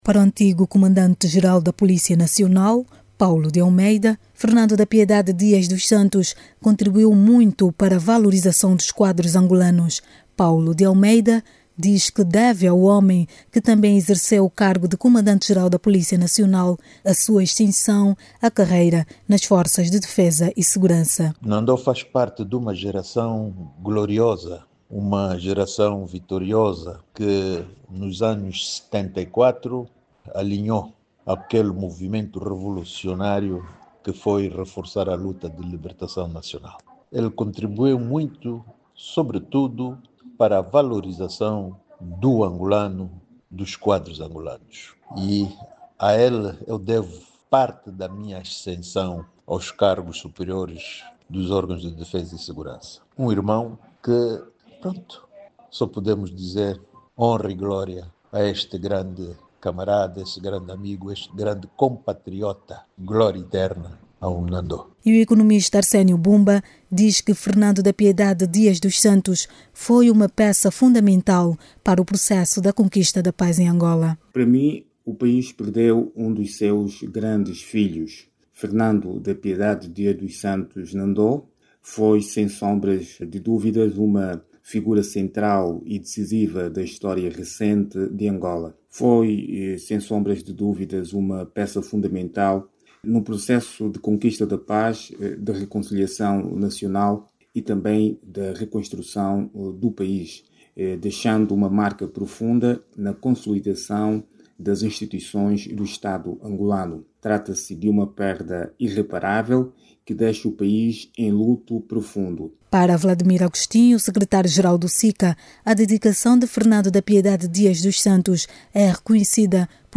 As vozes ouvidas realçam o papel desempenhado por Fernando da Piedade Dias dos Santos, lembrado com elevada estima.